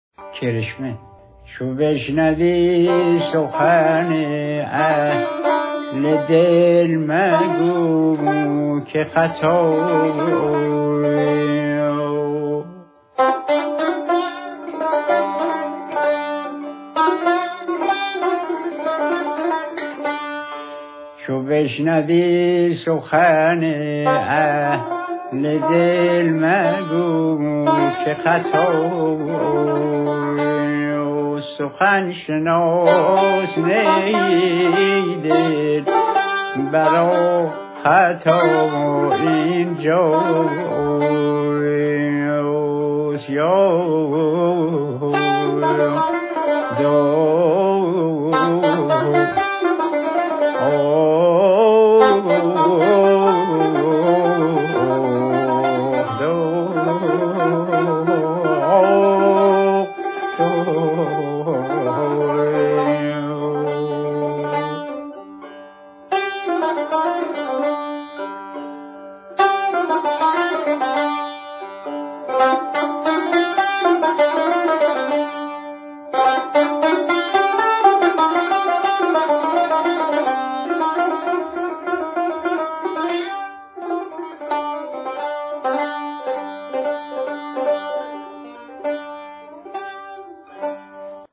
بشنوید بخشی از اجرای ردیف را با صدای عبدالله دوامی و تار نوازی محمدرضا لطفی